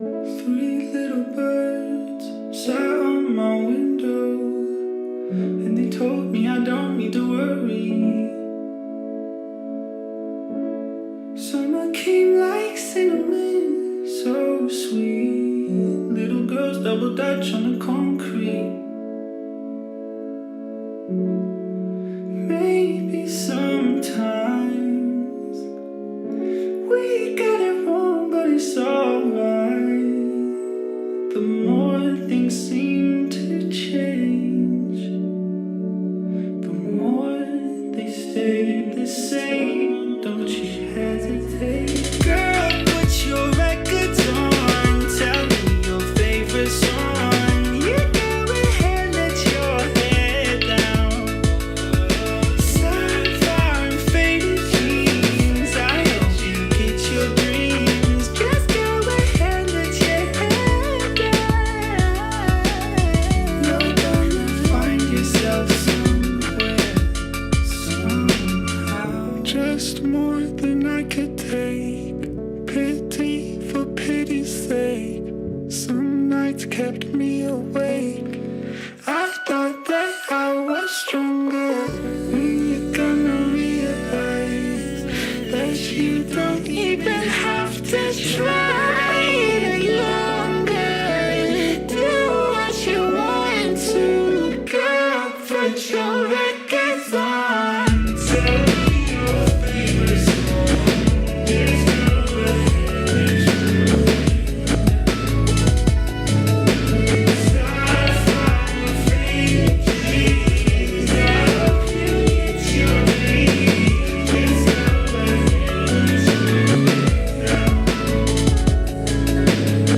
BPM75-91
Audio QualityLine Out
Comments[LO-FI]